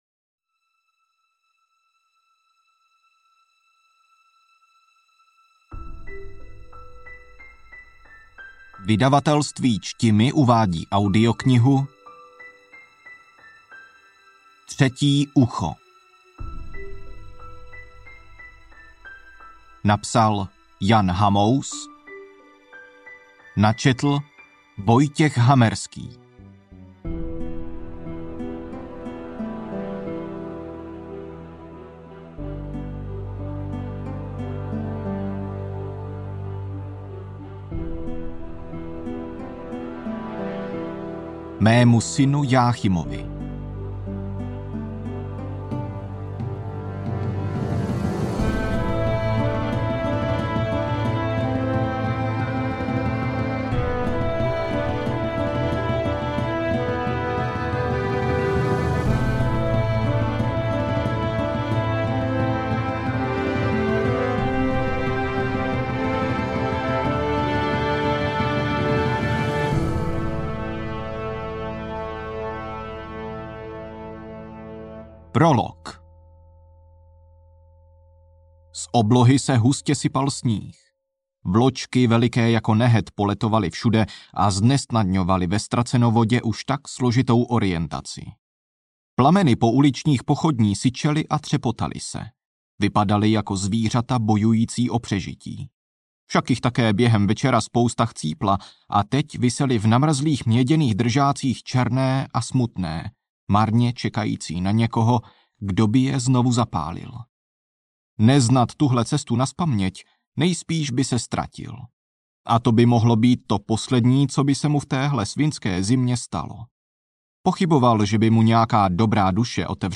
Každý uživatel může po zakoupení audioknihy daný titul ohodnotit, a to s pomocí odkazu zaslaný v mailu.